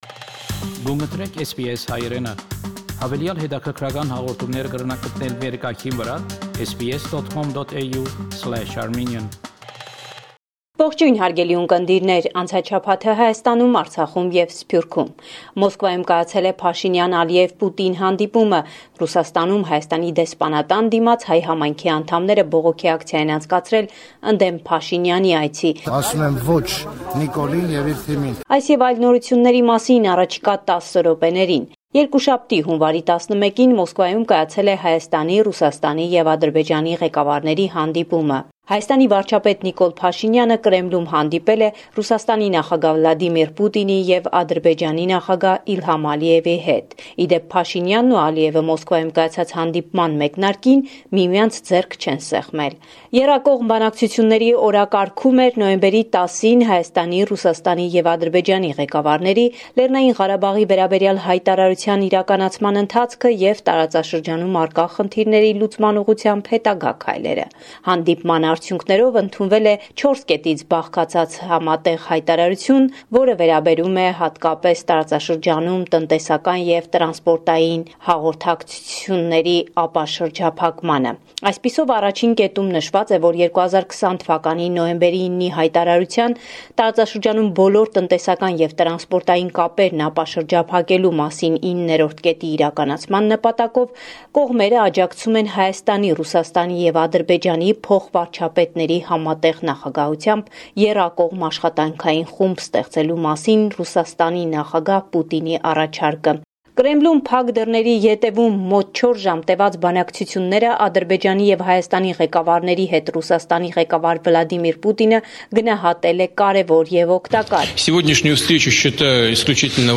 News from Armenia, Artsakh and the Diaspora